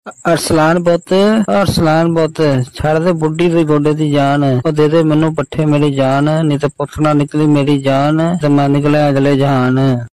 funny goat